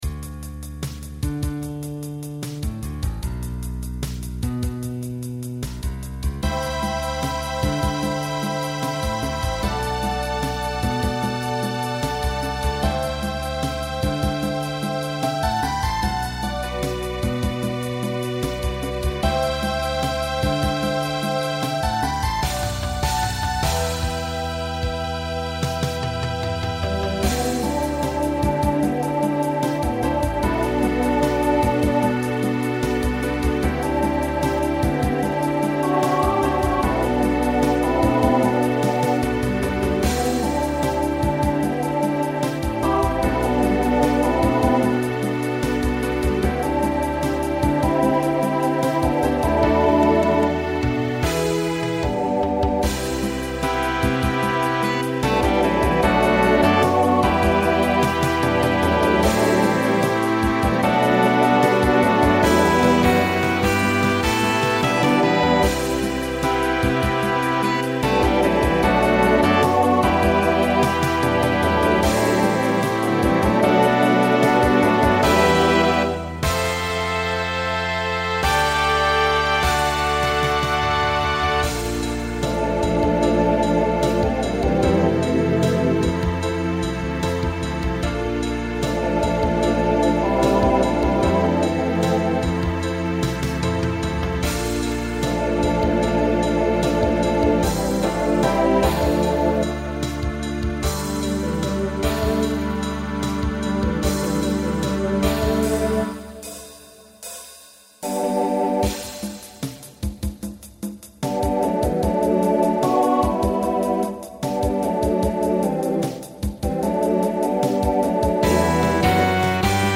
TTB/SSA